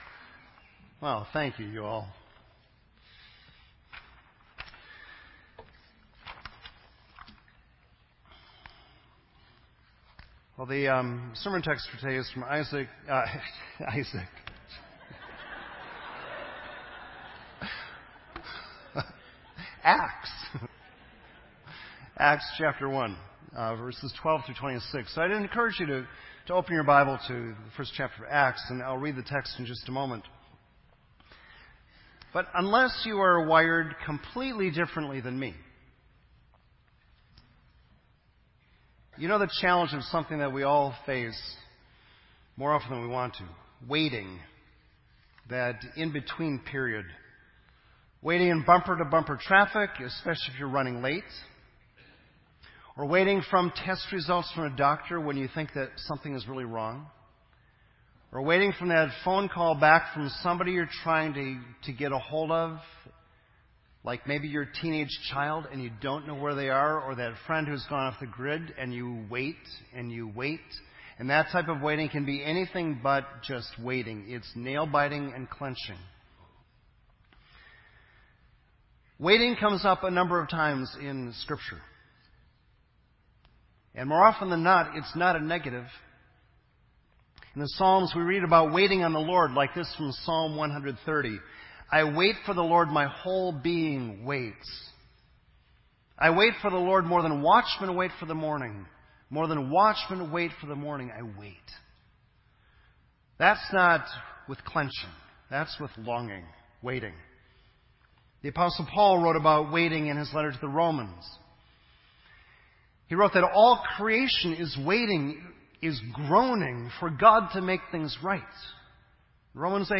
This entry was posted in Sermon Audio on May 9